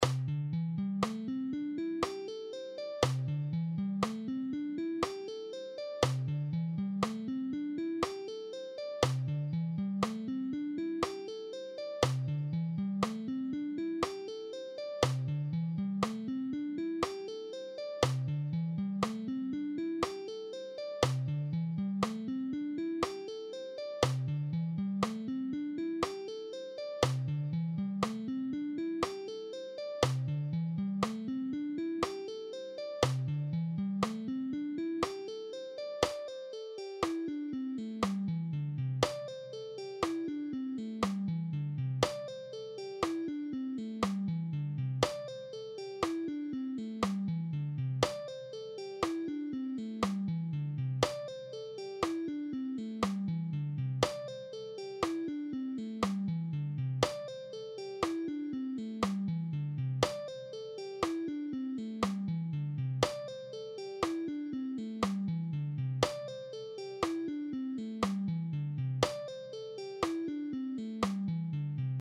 All these guitar exercises are in ‘C’
Lesson 10: String Skipping Pentatonic and Tapping Guitar Lesson
10.-String-Skipping-Pentatonic-and-Tapping-Guitar-Lesson.mp3